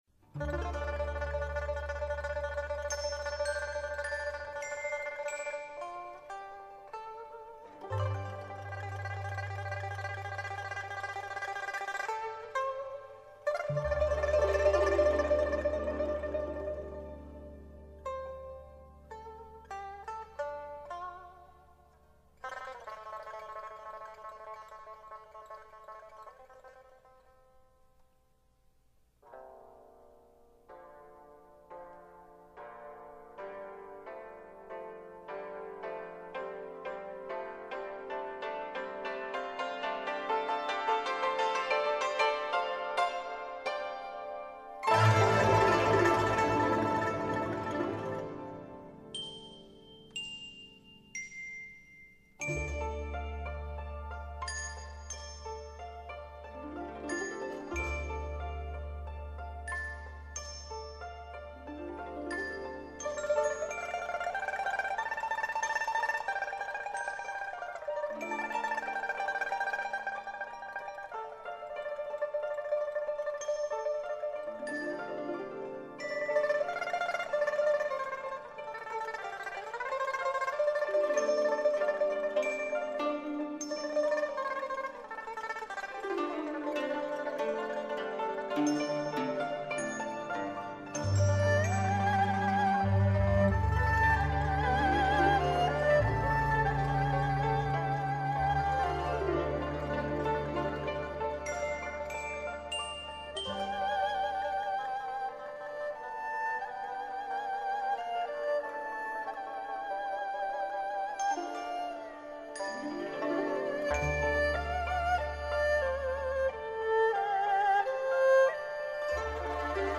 以发烧片呈现传统民谣，音色绝美